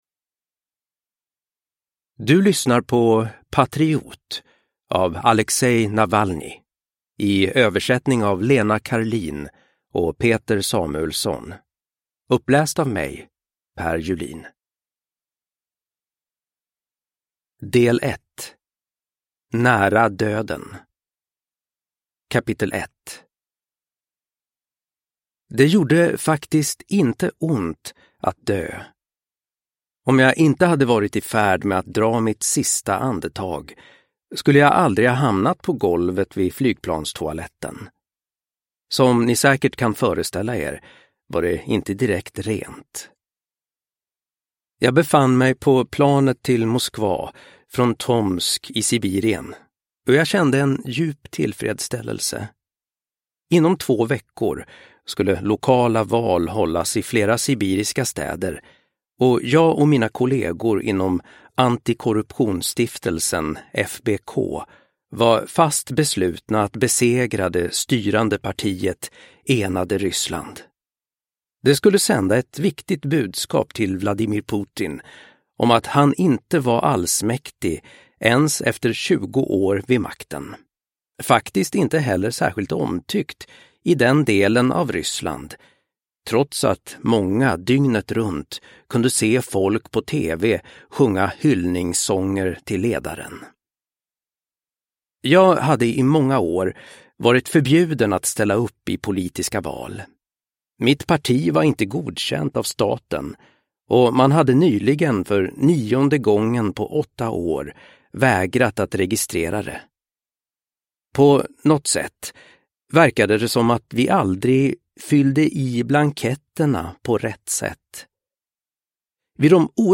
Patriot – Ljudbok